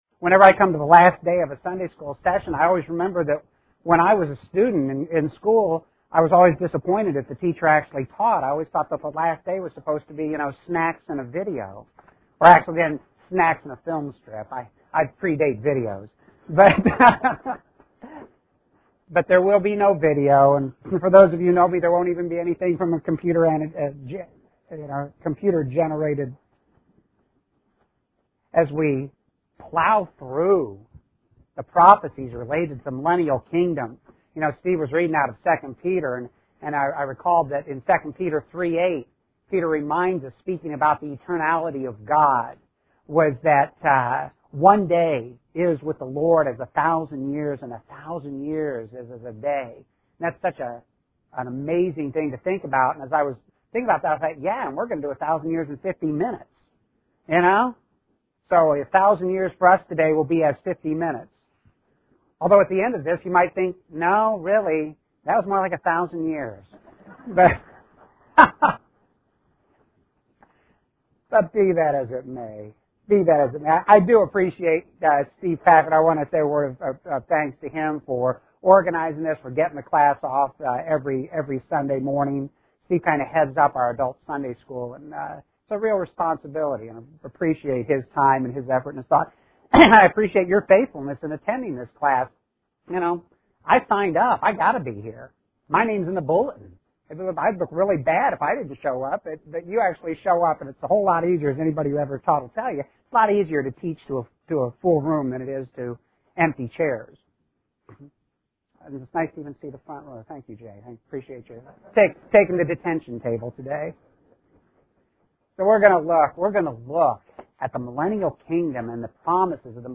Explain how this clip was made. Adults / 1st Service